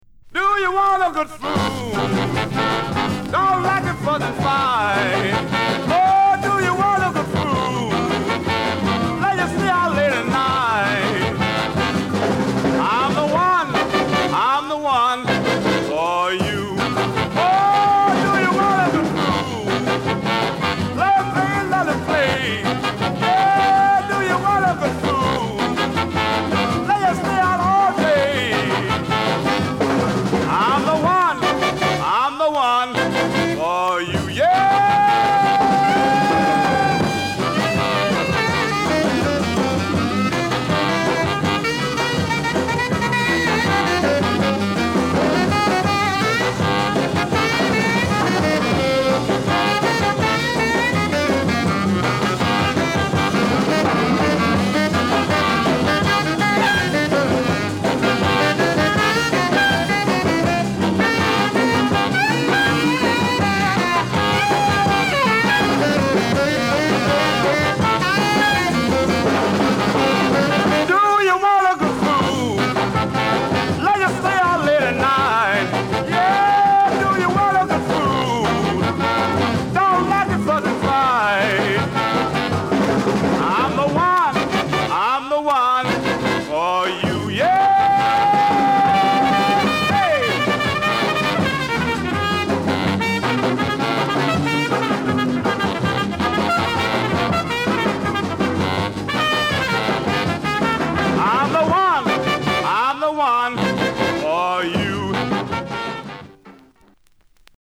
勢いたっぷりの演奏にぶっといヴォーカルが絡むロウでファストなガレージR&B。